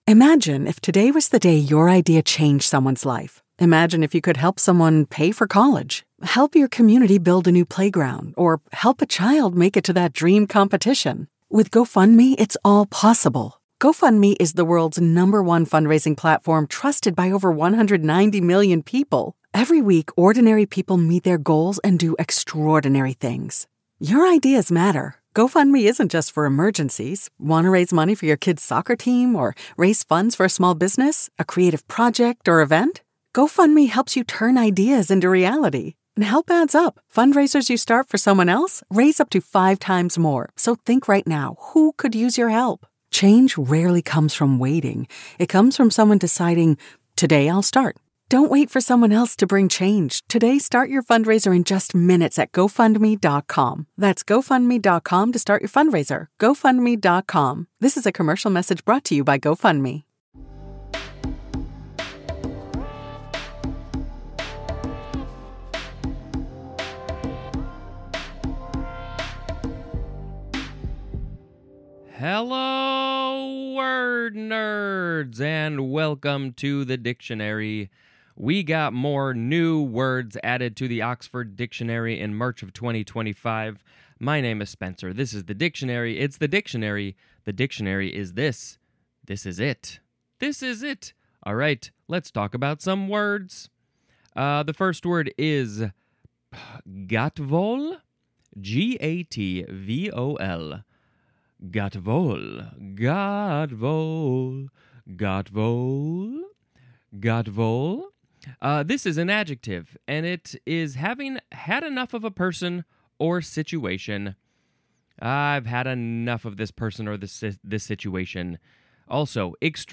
I read the English Dictionary in short sections, comment on it, make bad jokes, and sometimes sing little songs.
It’s an evolving project so the letter ”A” had lower quality, plus less commenting and jokes.